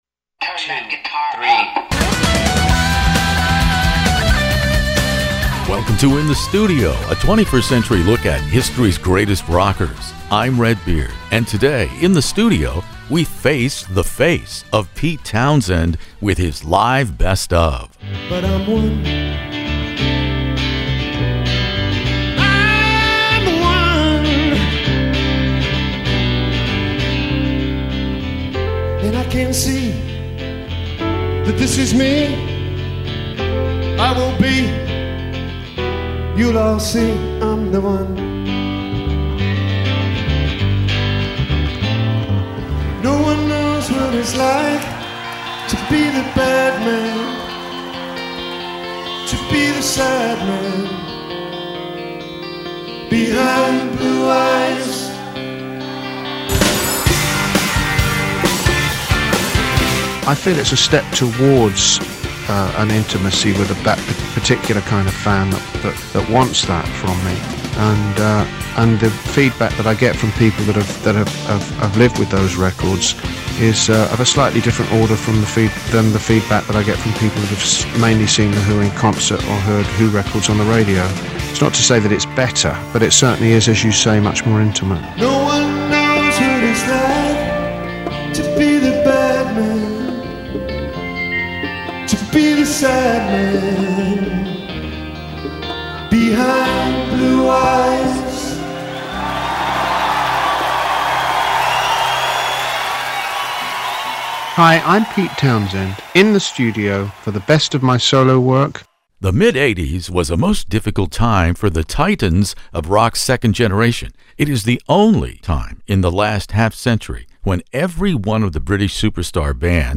What has been made available from this mid-Eighties transitional period is Pete Townshend’s live performance with an all-star band he dubbed the “Deep End”, which included Pink Floyd guitarist David Gilmour and terrific timekeeper Simon Phillips on drums, performing live at Cannes France in January 1986 and broadcast on German television.